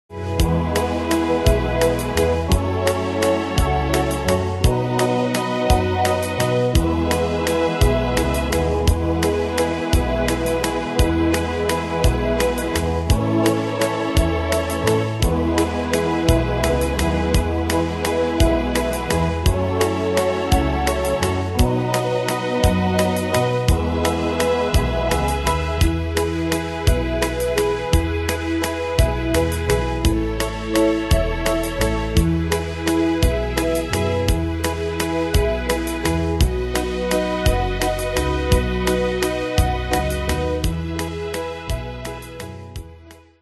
Danse/Dance: Valse/Waltz Cat Id.
Pro Backing Tracks